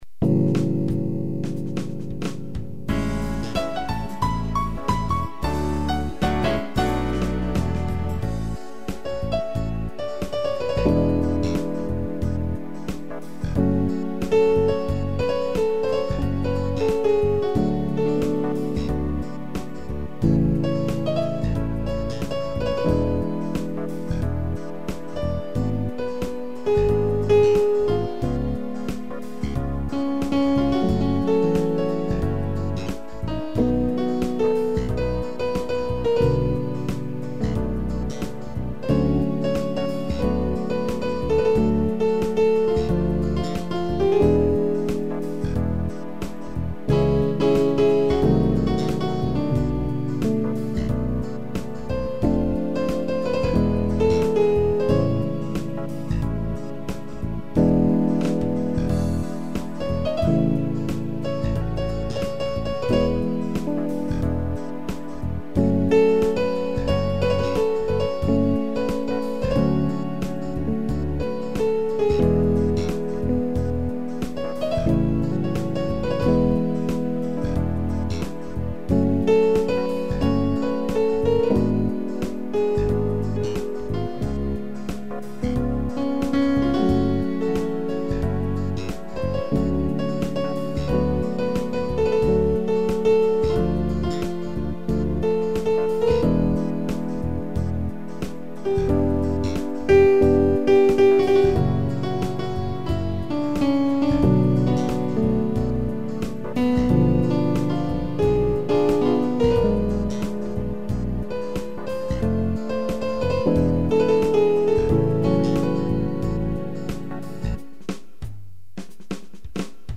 piano, sax e flauta
instrumental